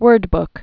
(wûrdbk)